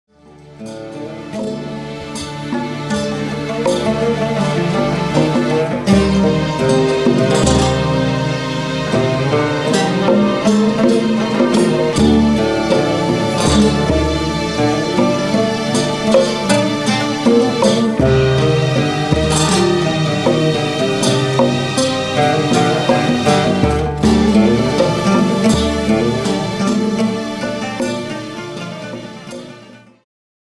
ジャンル Progressive
シンフォニック系
ワールドミュージック
民族楽器とシンフォニック・ロックが絶妙に絡み合い異郷に誘う！
oud
acoustic guitar
tombak